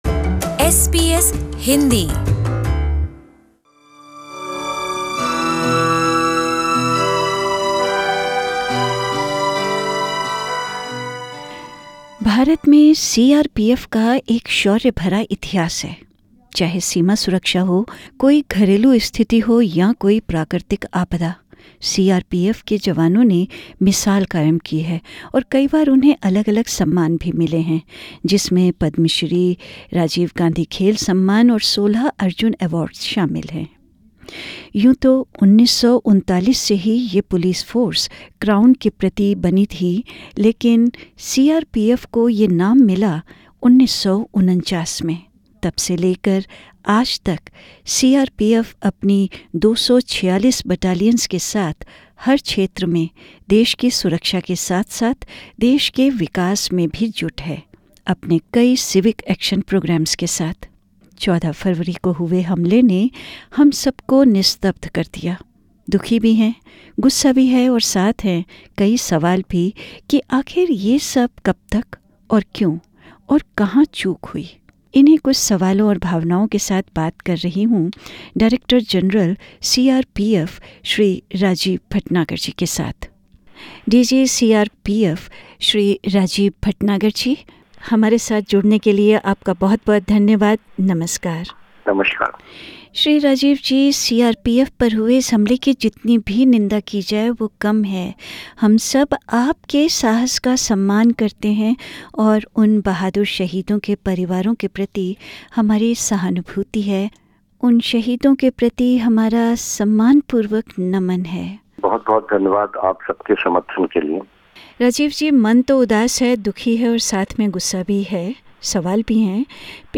The attack on CRPF soldiers on 14th Feb 2019 in Pulwama killed at least 40 soldiers. Speaking with SBS Hindi, the Director General of the CRPF, Mr Rajeev R Bhatnagar said that necessary control action has been taken along with other security forces in the area.